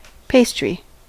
Ääntäminen
US : IPA : [ˈpeɪs.tɹi]